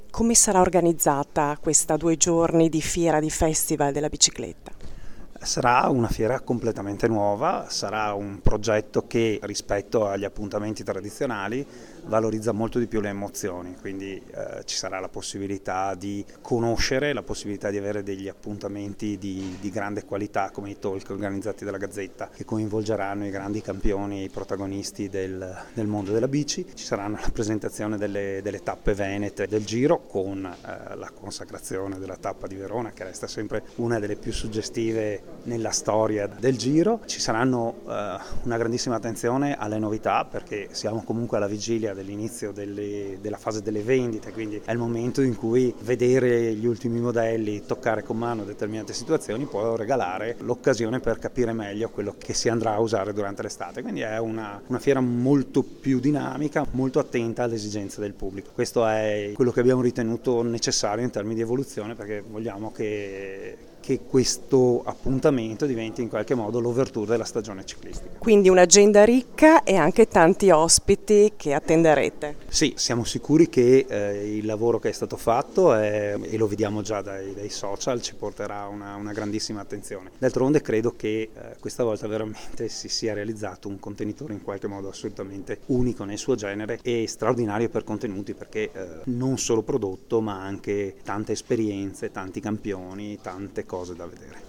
LE INTERVISTE DELLA NOSTRA CORRISPONDENTE